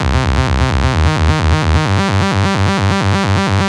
Quadro-Octaves C 130.wav